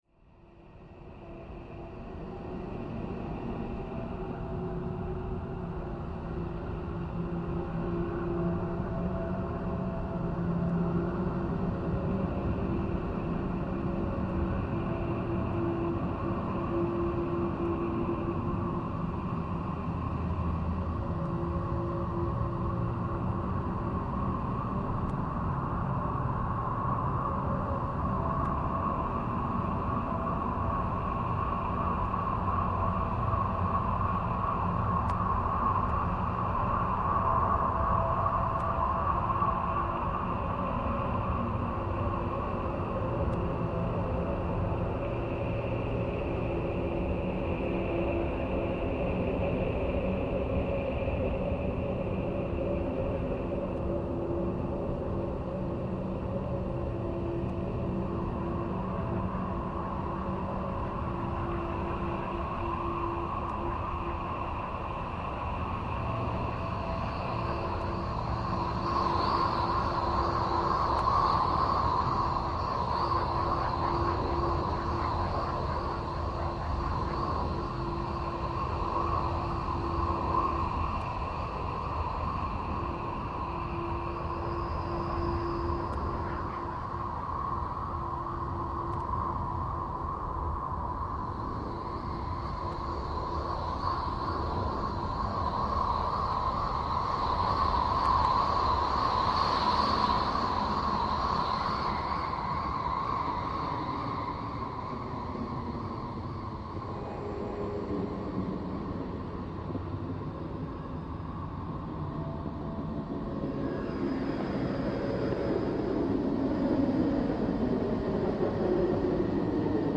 Sound installation
Concrete mixer, coal, volcanic rocks, cement, sand, earth, Indian ink, pigments, 2017
On the ground, a black concrete mixer suggests the pile of rubble against its metal walls. Monochromatic minerals collide in the drum: volcanic stone, coal and black sand.